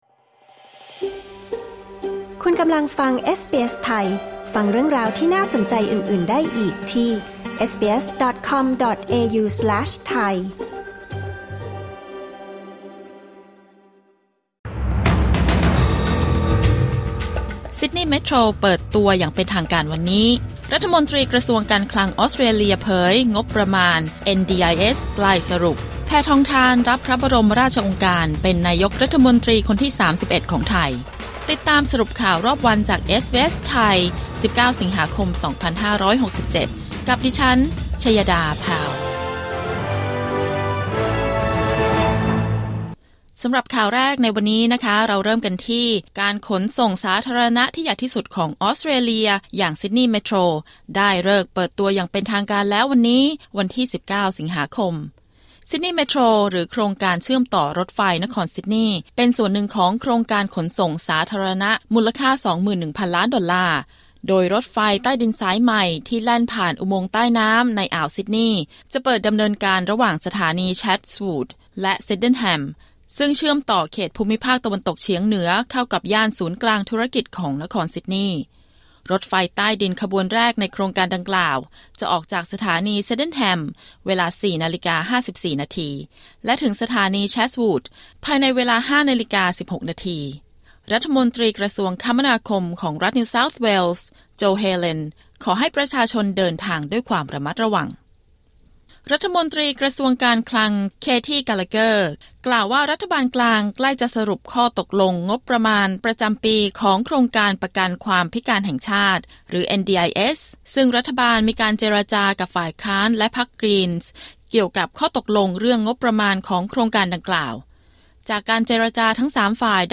สรุปข่าวรอบวัน 19 สิงหาคม 2567